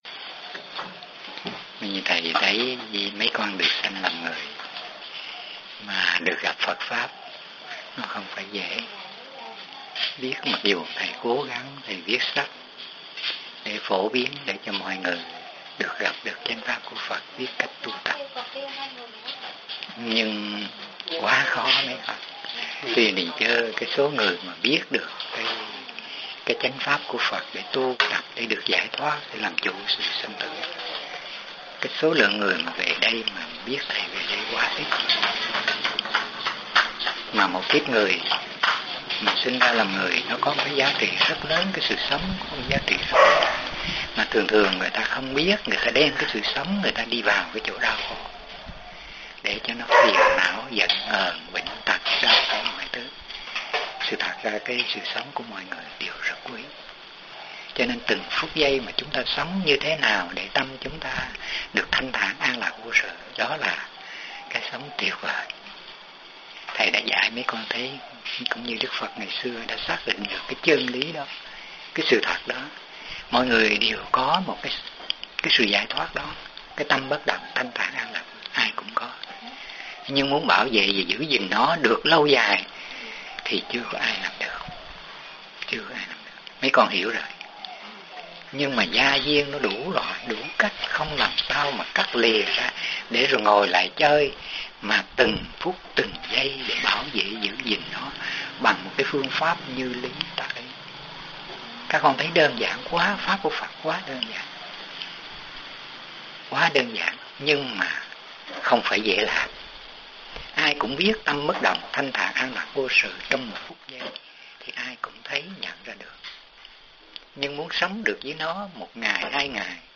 Người nghe: Phật Tử